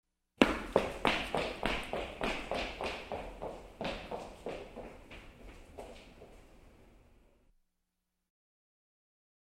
Звук бегущего человека по бетонному полу